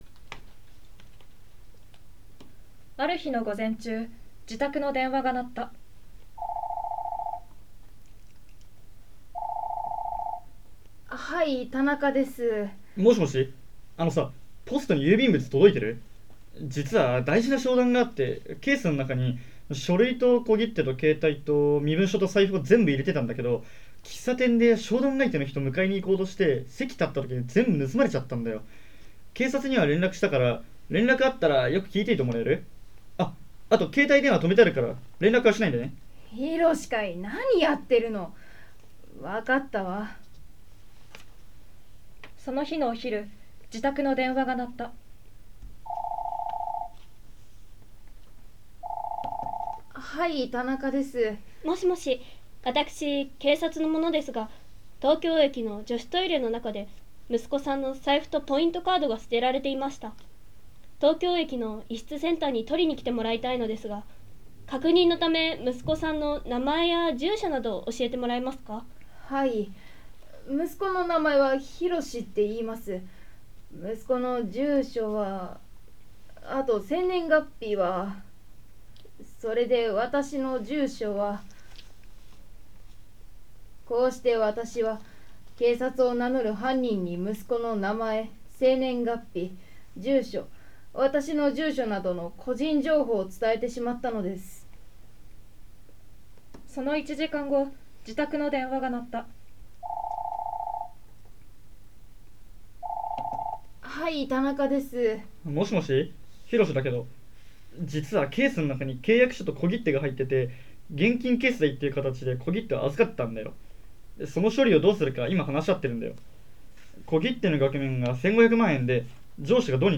オレオレ詐欺被害防止のためのラジオドラマが完成
座間総合高校を特殊詐欺被害サポート校に任命し、同校演劇部にラジオドラマを作成していただきました。